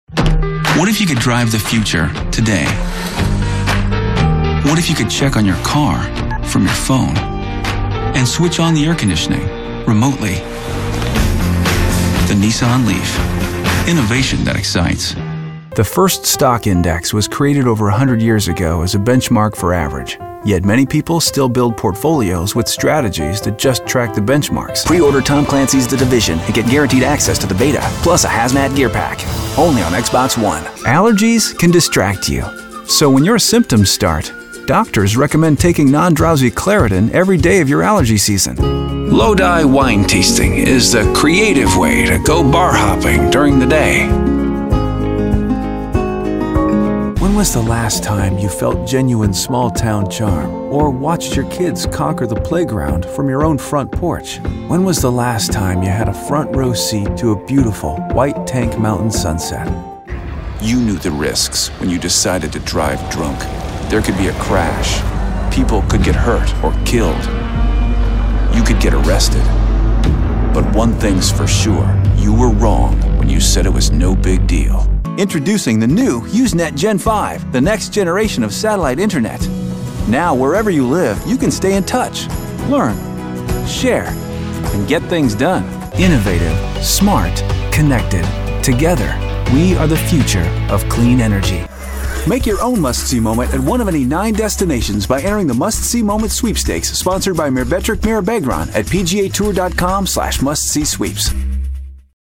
Guy Next Door, Trustworthy, Honest, Intelligent, Strong, Believable, Real, Energetic, Conversational, Tech Savvy
Sprechprobe: Werbung (Muttersprache):